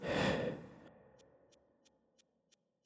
exhale.mp3